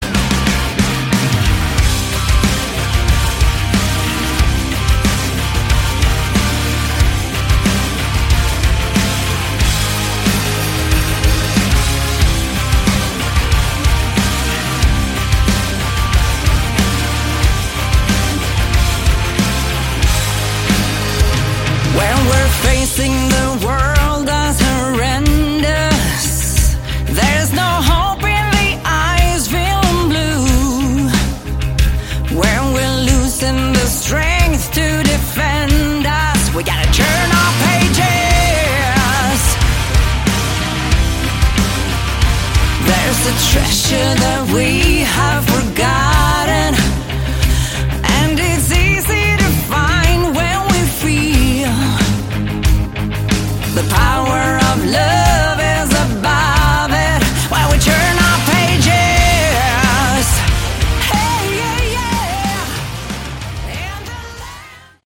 Category: Melodic Rock
keyboard/Hammond organ